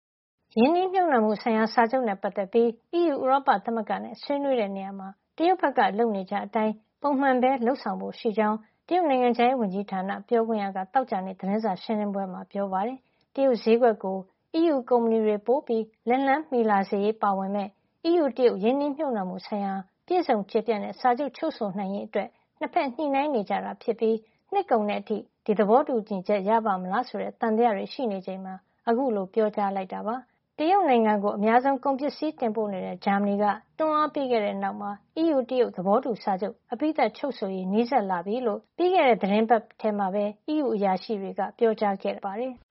တရုတ်နိုင်ငံခြားရေးဝန်ကြီးဌာန ပြောခွင့်ရ Wang Wenbin
ရင်းနှီးမြှုပ်နှံမှုဆိုင်ရာ စာချုပ်နဲ့ပတ်သက်ပြီး အီးယူ ဥရောပသမဂ္ဂနဲ့ ဆွေးနွေးတဲ့နေရာမှာ တရုတ်ဘက်က လုပ်နေကျအတိုင်း ပုံမှန်ပဲ လုပ်ဆောင်ဖို့ရှိကြောင်း တရုတ်နိုင်ငံခြားရေးဝန်ကြီးဌာန ပြောခွင့်ရ Wang Wenbin က သောကြာနေ့ သတင်းစာ ရှင်းလင်းပွဲမှာ ပြောပါတယ်။